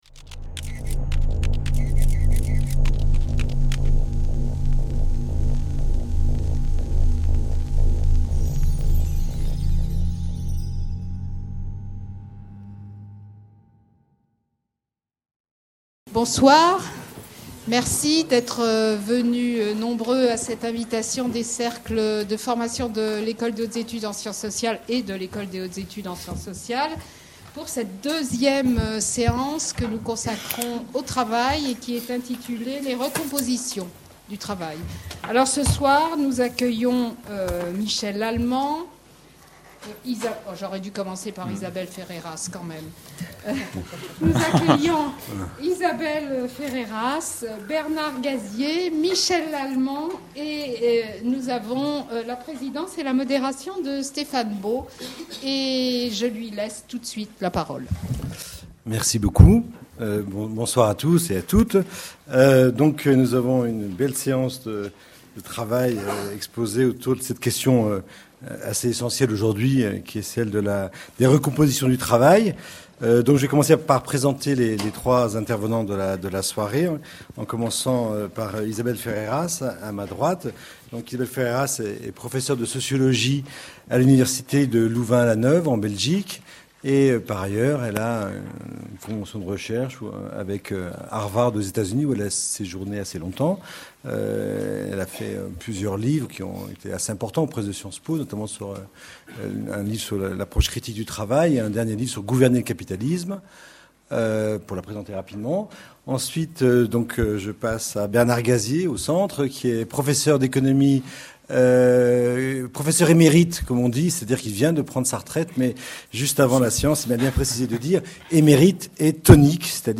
questions de la salle